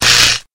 На этой странице собраны звуки электрошокера – от резких разрядов до характерного жужжания.
Звук работающего электрошокера